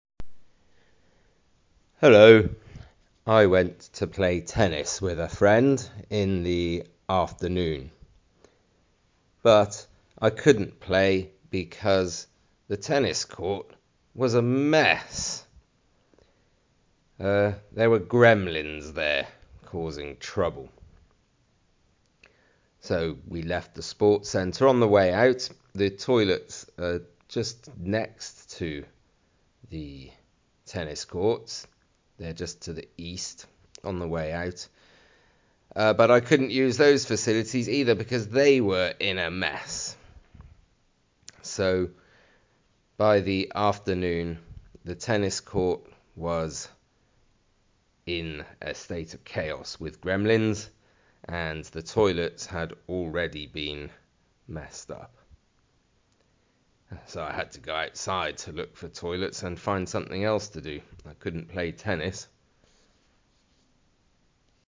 Listen to the tennis player
sport-centre-tennis-player.mp3